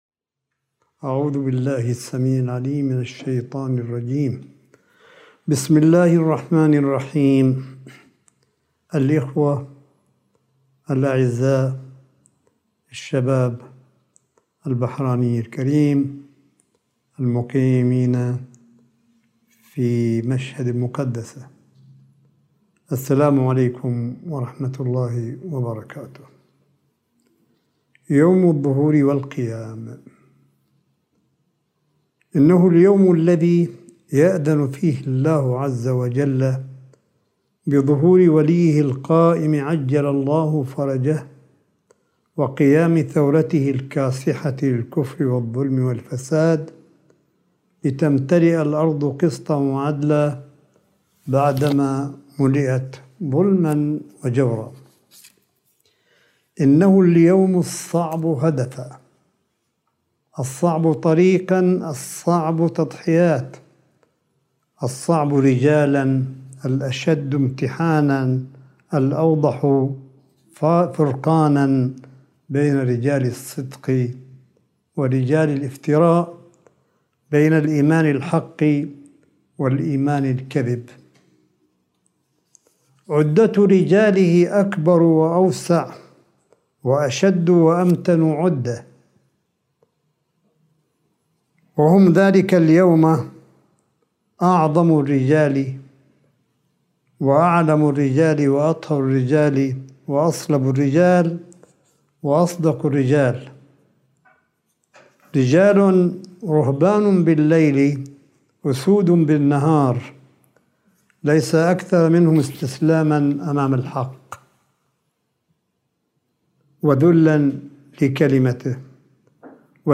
ملف صوتي لكلمة آية الله قاسم في ذكرى مولد الإمام القائم وافتتاح الحسينية البحرانية بمشهد المقدسة – 14 شعبان 1442هـ